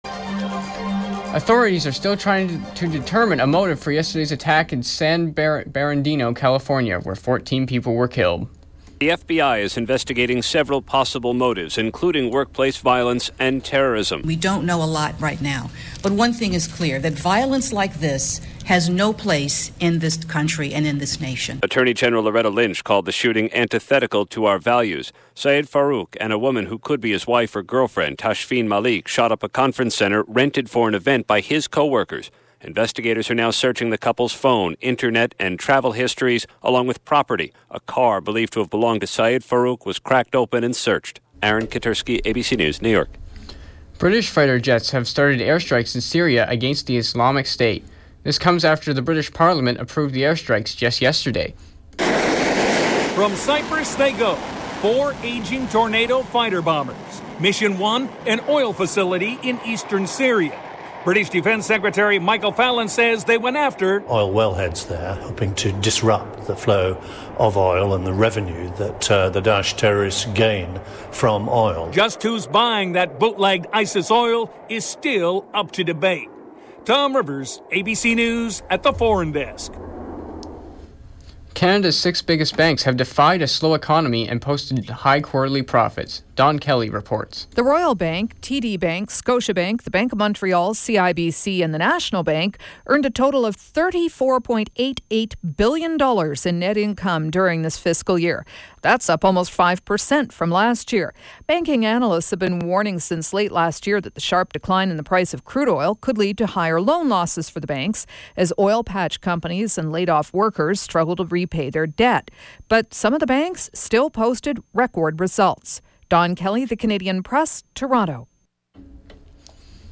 91x newscast – Thursday, Dec. 3, 2015 – 4 p.m.